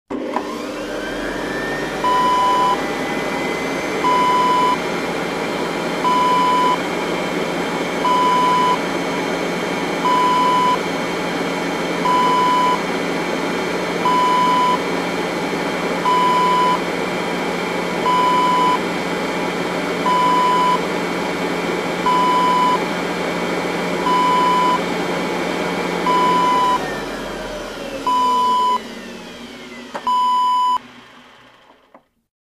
Kitchen Aid Mixer Sound Effect Wav
Description: Kitchen aid mixer switches on, runs and switches off
Properties: 48.000 kHz 16-bit Stereo
A beep sound is embedded in the audio preview file but it is not present in the high resolution downloadable wav file.
kitchen-aid-preview-1.mp3